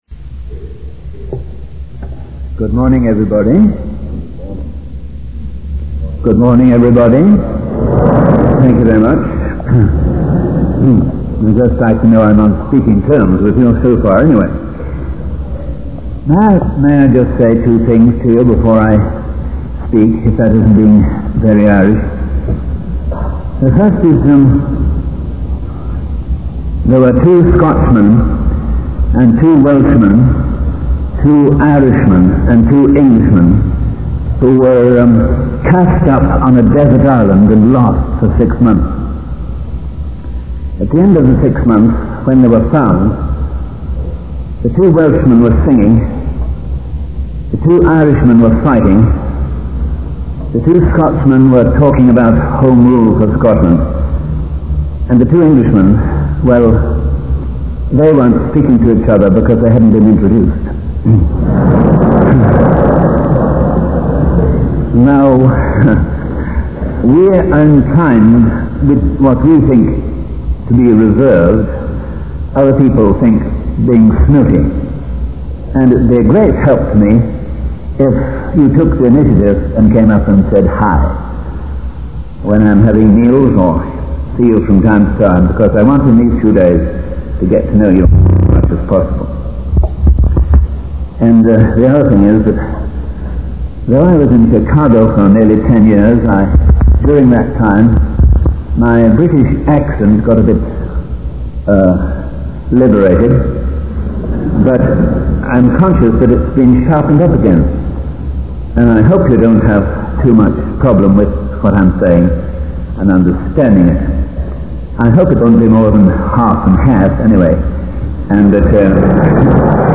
In this sermon, the speaker emphasizes the importance of recognizing the smallness of the world and the great task that lies before the church. He acknowledges the challenges and distractions that can hinder spiritual growth and commitment. The speaker also highlights the need to restore our relationship with God and make Him the Lord of our lives.